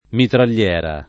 [ mitral’l’ $ ra ]